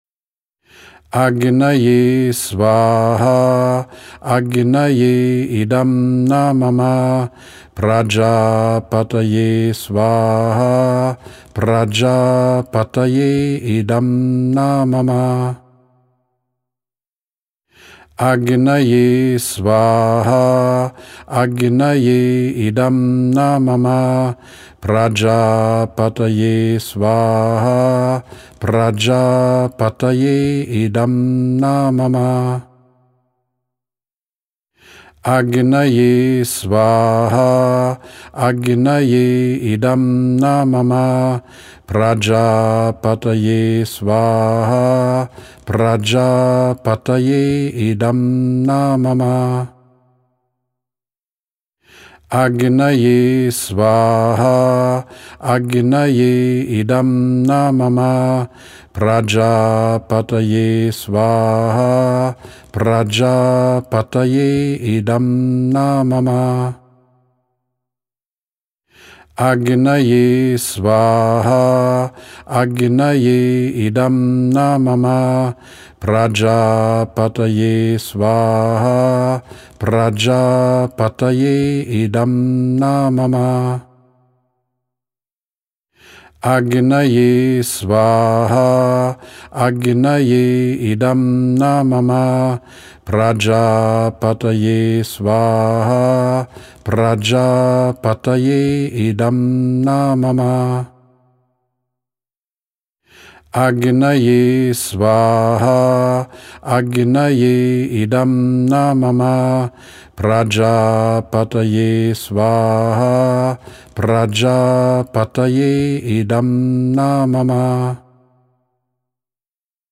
Vedische Feuerzeremonien
Die zu Sonnenauf- und Sonnenuntergang bei der Durchführung von Agnihotra gesungenen Sanskrit- Mantras stehen in Resonanz mit dem Biorhythmus der Sonne.
Agnihotra-Und-Yagna-Mantras-02-Sonnenuntergang-9-Mal.mp3